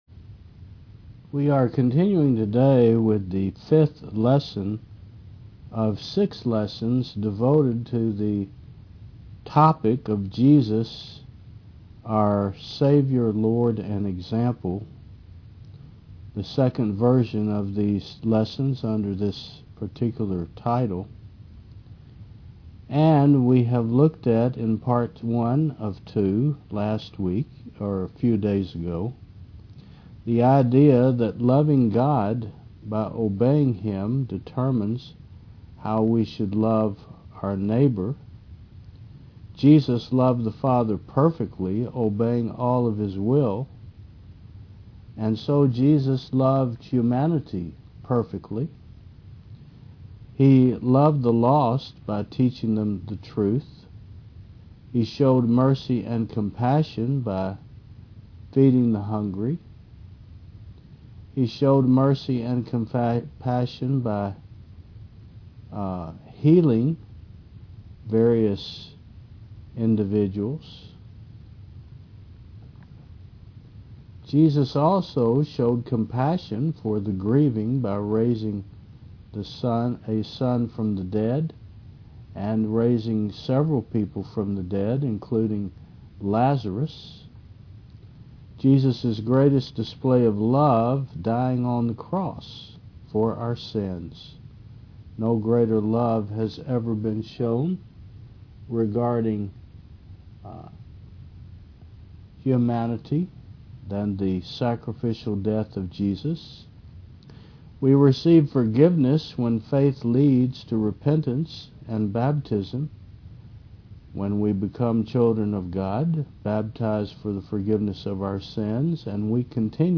Jesus Our Savior Lord And Example v2 Service Type: Thu 10 AM What does it mean to love?